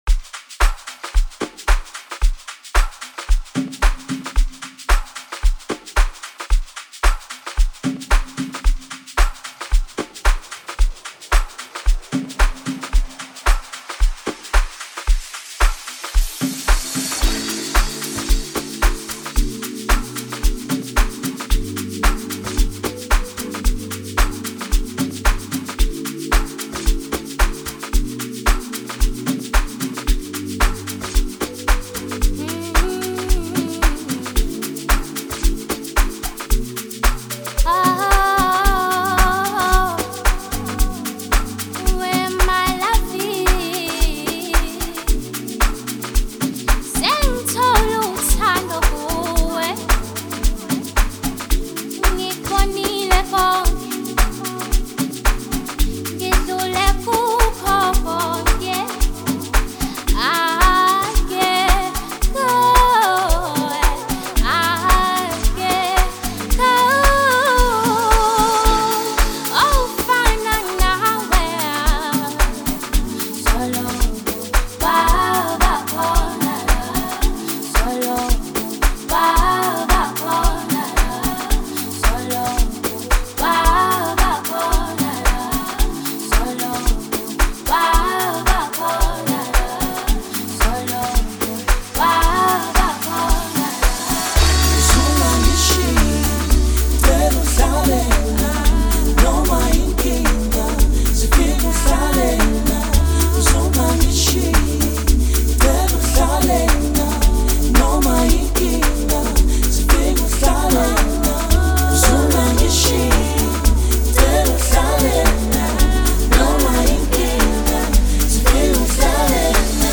genre-defying track that blends various musical styles